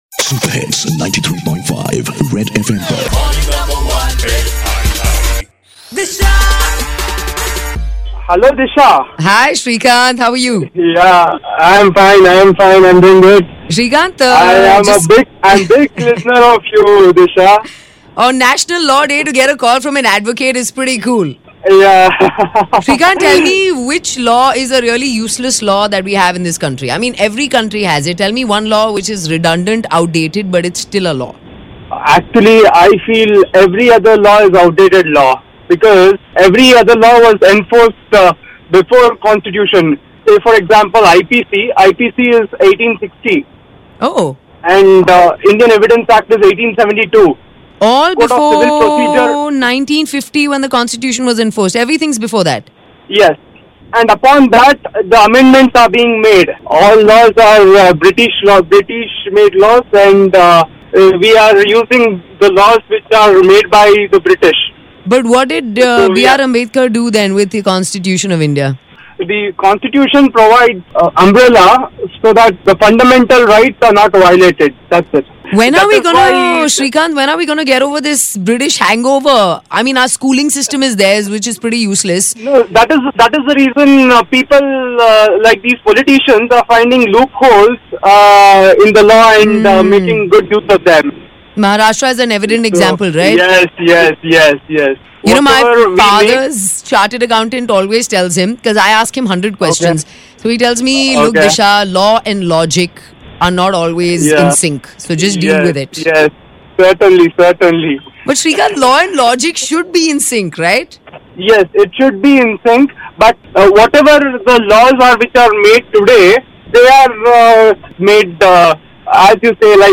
have a discussion on Indian Constitution & myths about Lawyers